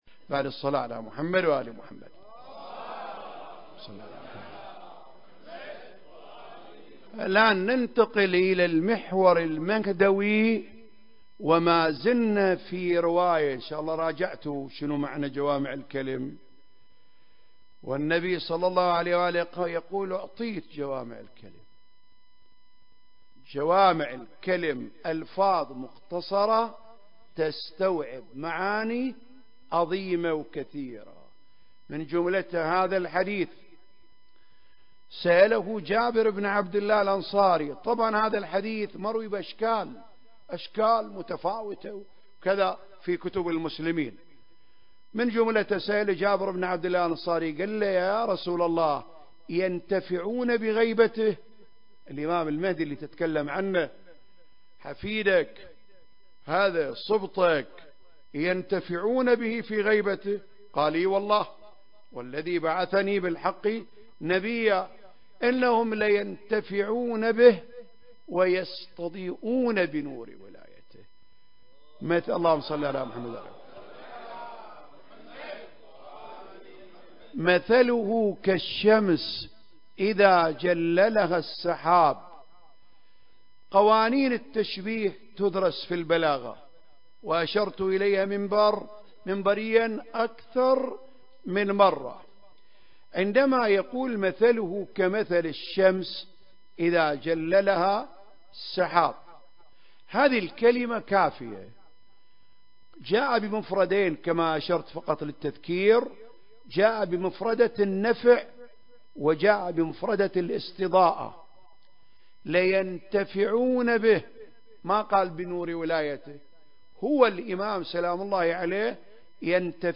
سلسلة محاضرات في رحاب الإمام صاحب الزمان (عجّل الله فرجه) (2) المكان: الحسينية الهاشمية/ الكويت التاريخ: 2023